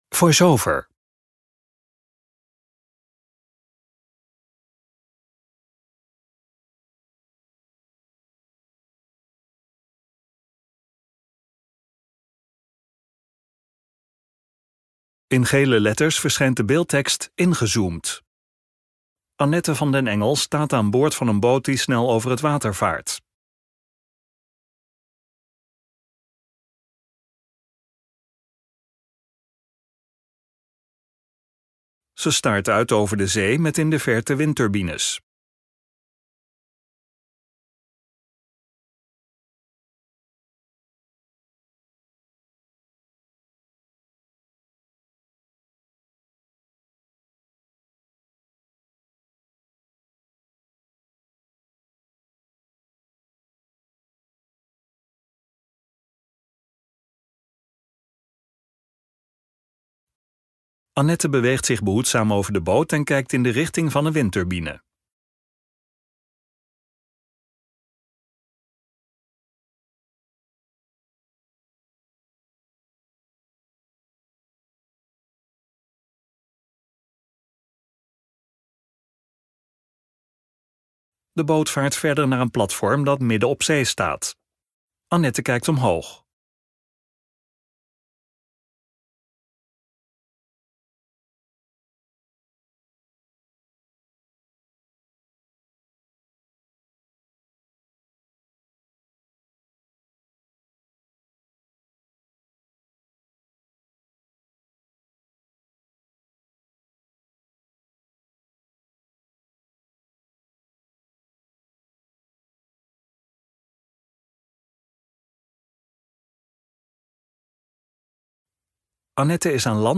Vlog 19 februari 2023